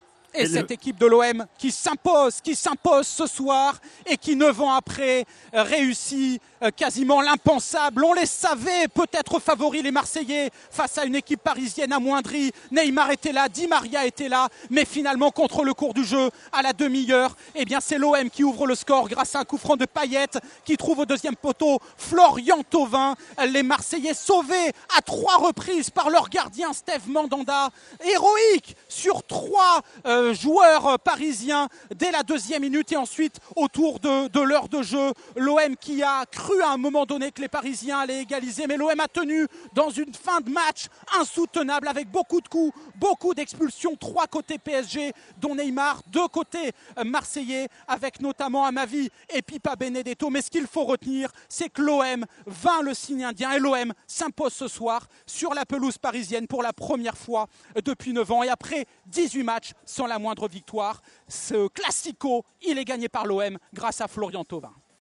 Revivez le but de l’attaquant de l’OM commenté  en direct par notre partenaire France Bleu Provence :